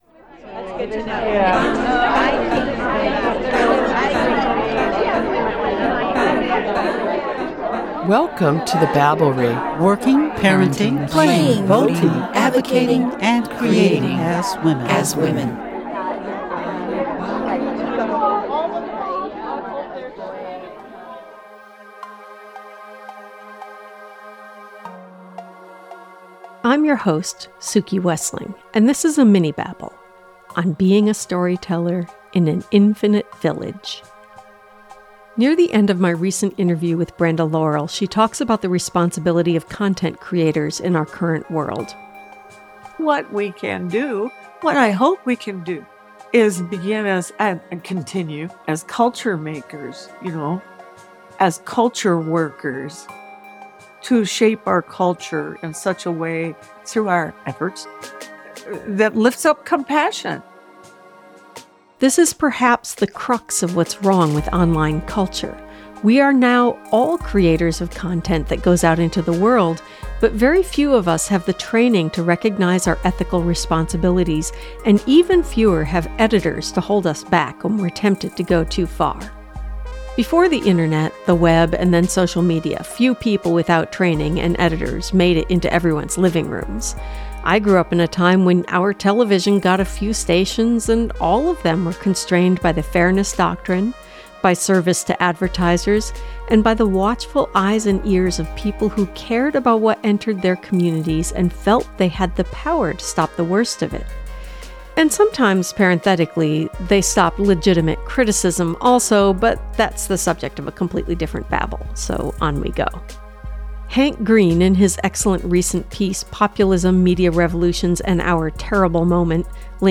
Near the end of my recent interview with Brenda Laurel, she talks about the responsibility of content creators in our current world.